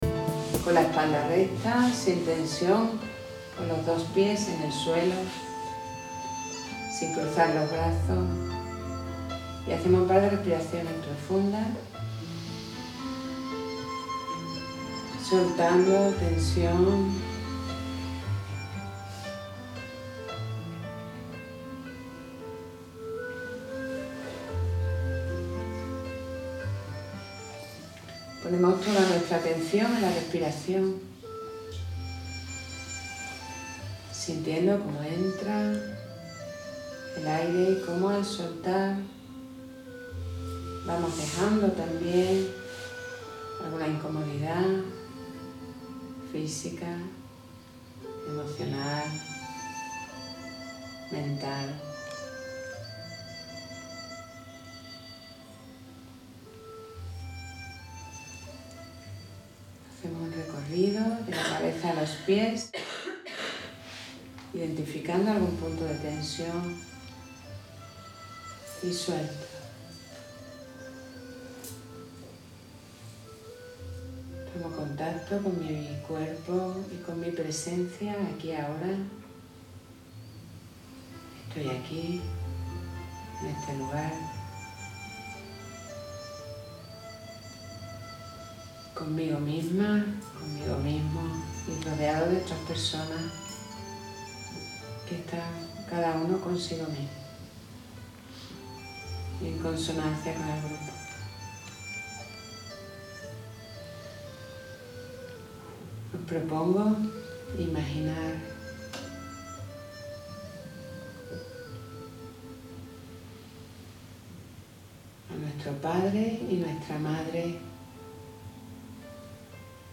1-Meditacion-Inclinacion-ante-la-vida-M6.mp3